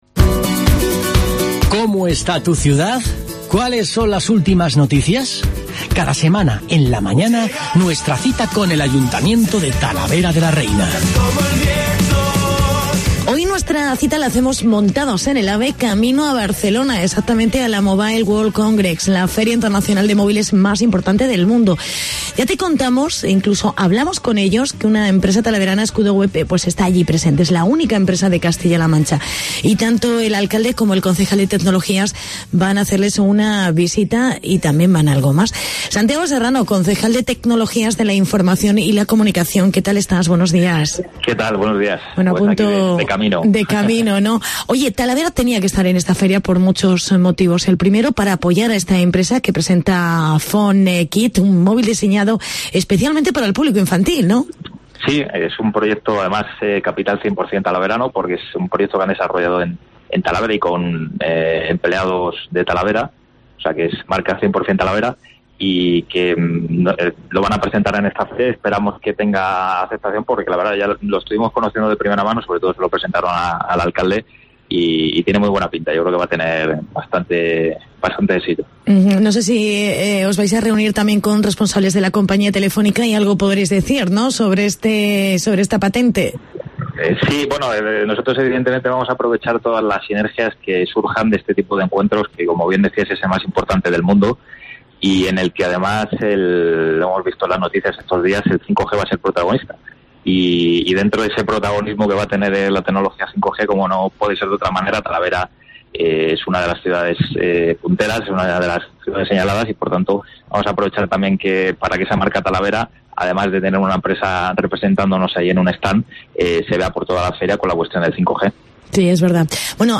Entrevista con el concejal Santiago Serrano